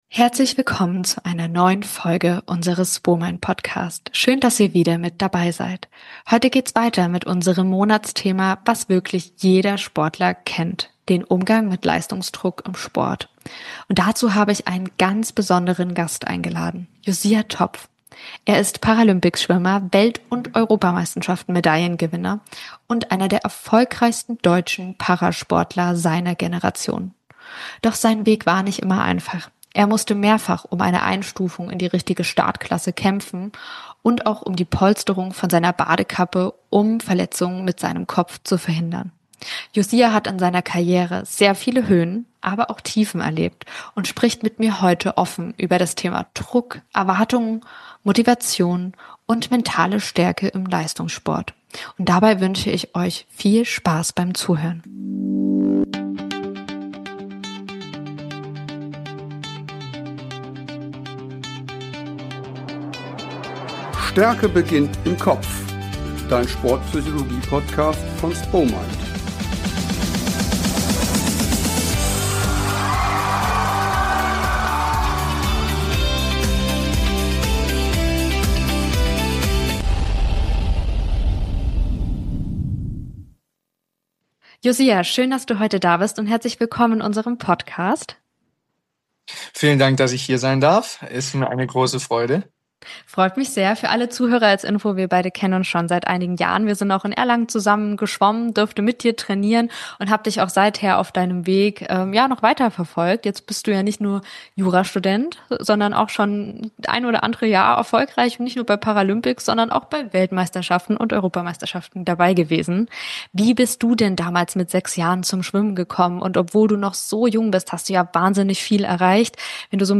Beschreibung vor 5 Monaten Heute haben wir Josia Topf zu Gast – Paralympics-Schwimmer, mehrfacher WM- und EM-Medaillengewinner und einer der erfolgreichsten Parasportler seiner Generation.